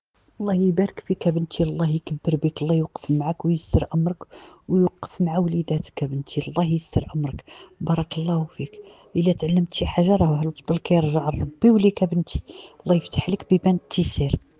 شهادات صوتية لبعض المشاركات 👇👇👇